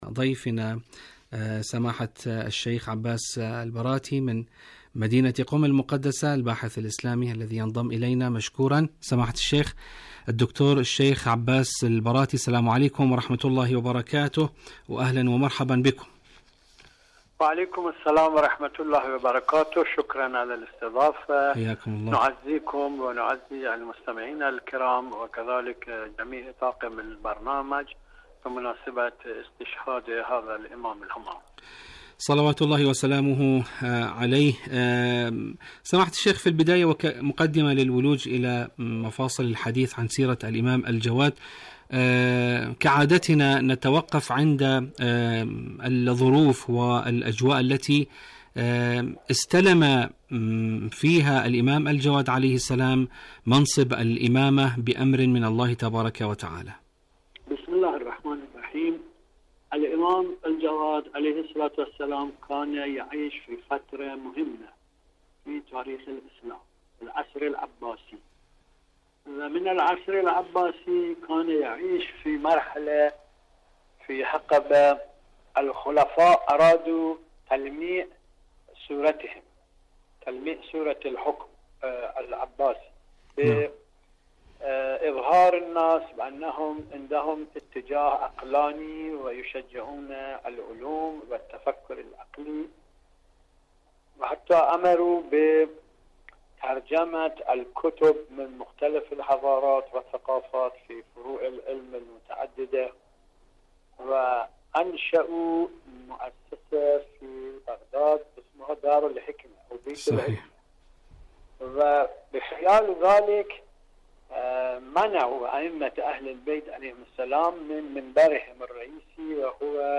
إذاعة طهران- استشهاد جواد الأئمة (ع): مقابلة إذاعية